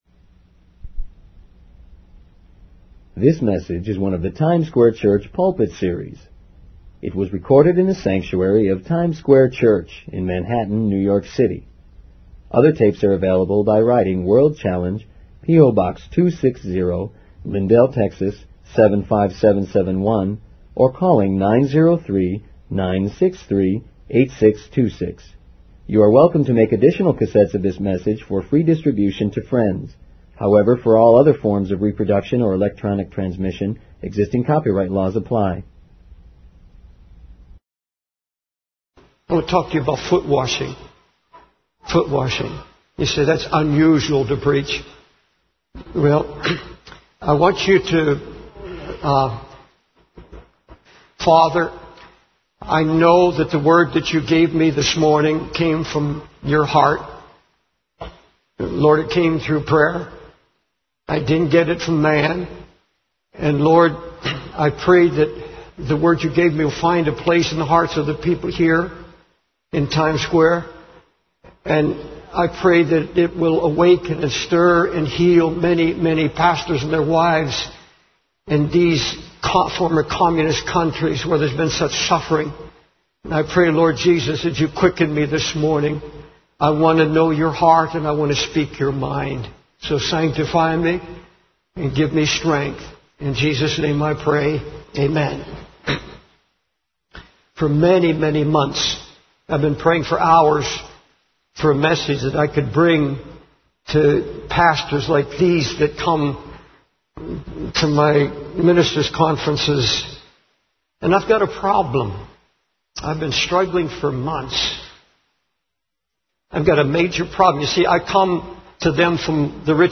In this sermon, the preacher emphasizes the importance of waiting in God's presence and being saturated in His holy word.